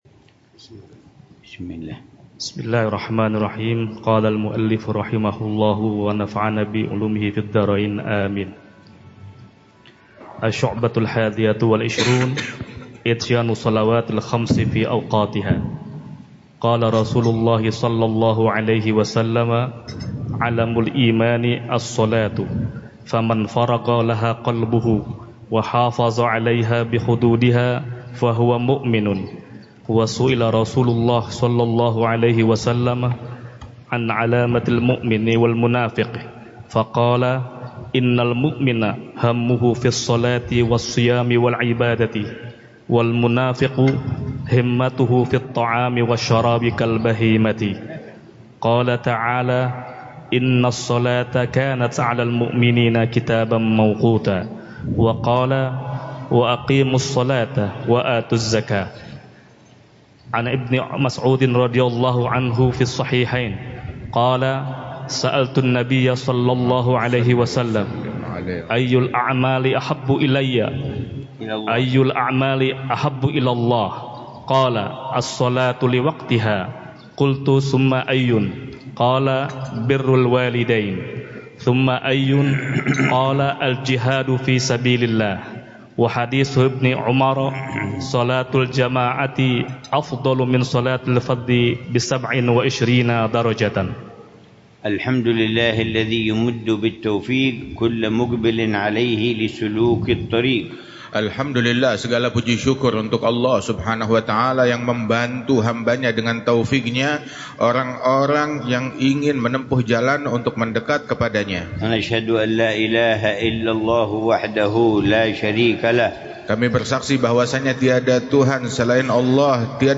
الدرس السابع للعلامة الحبيب عمر بن حفيظ في شرح كتاب: قامع الطغيان على منظومة شعب الإيمان، للعلامة محمد نووي بن عمر البنتني الجاوي في مسجد الاس